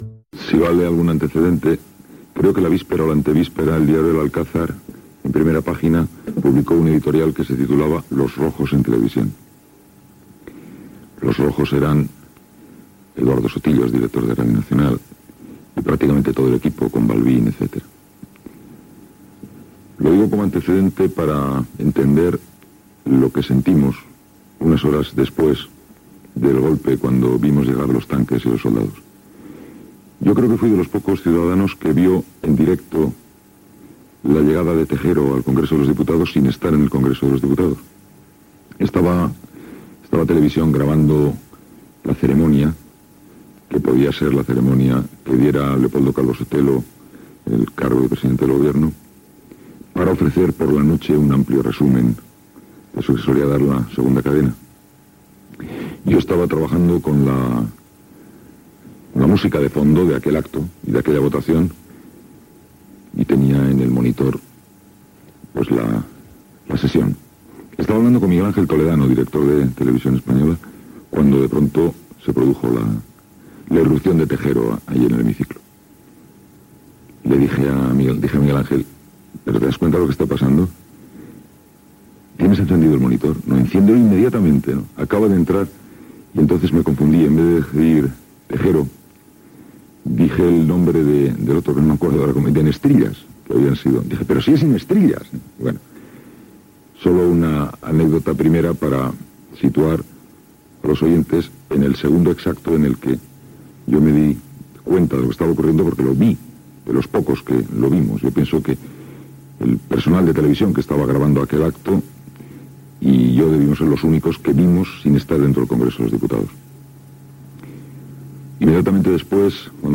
Fragment d'una entrevista a Iñaki Gabilondo sobre el cop d'estat del 23 de febrer de 1981. El record del Senyor Casamajor (Xavier Sardà) d'aquell dia
Entreteniment